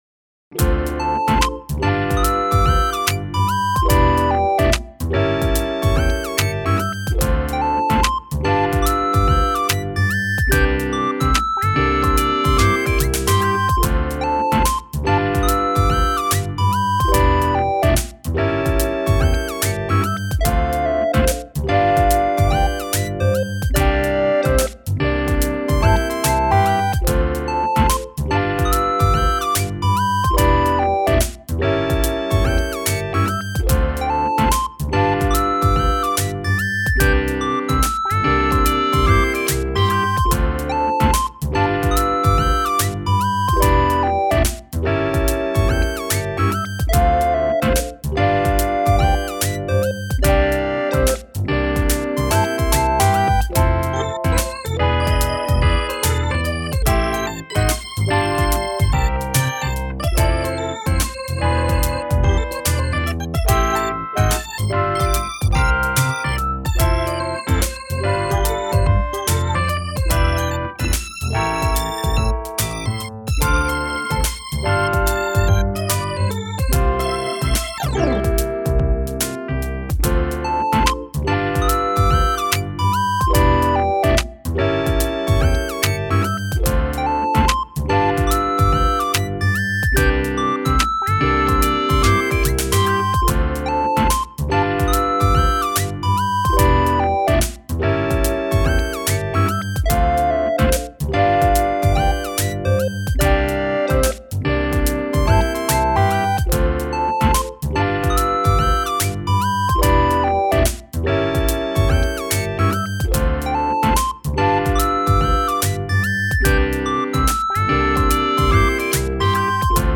のんびりまったり、おうちの時間もいいよね。